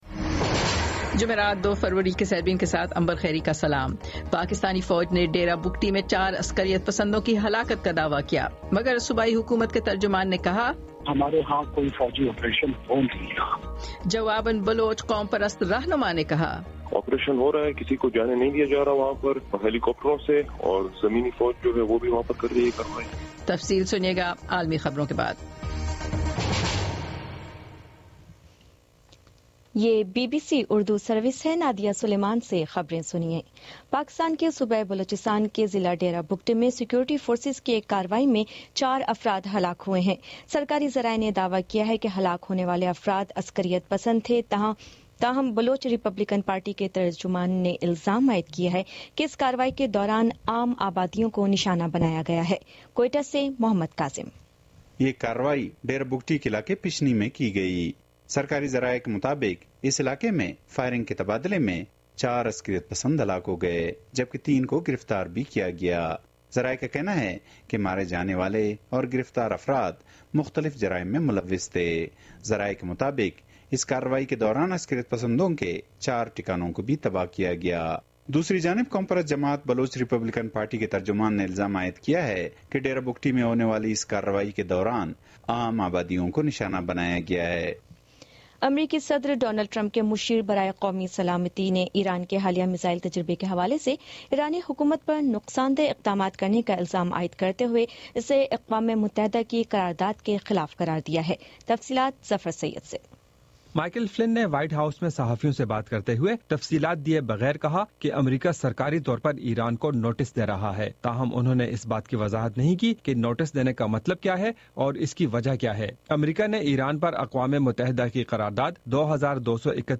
جمرات 02 فروری کا سیربین ریڈیو پروگرام